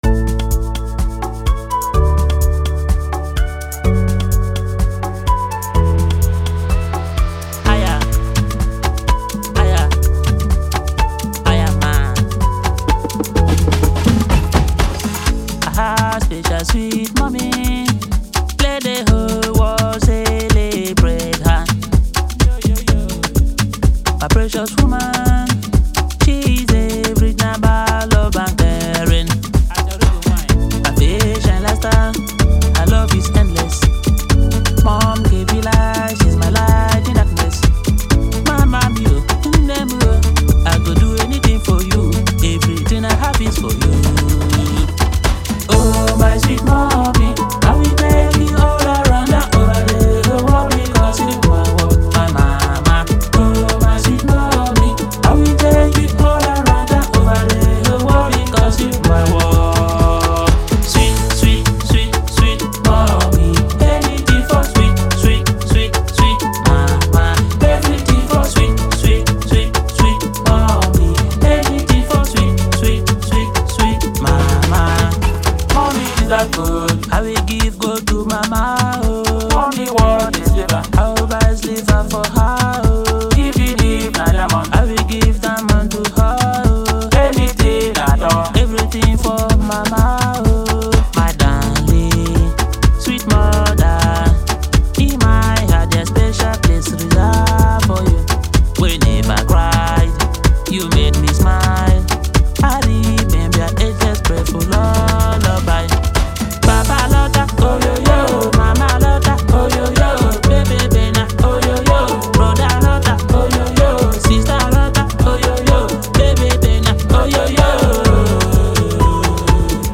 an exceptionally talented Nigerian musician and performer